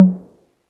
cch_perc_tom_high_tommy.wav